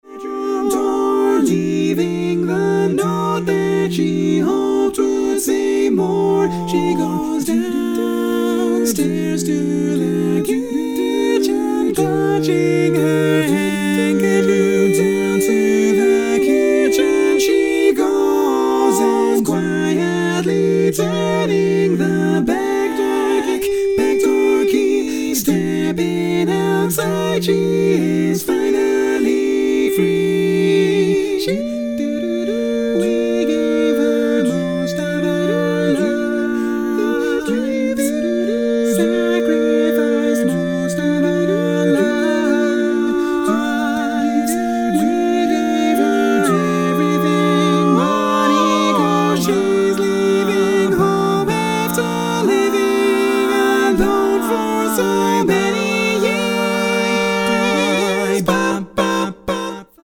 Full mix only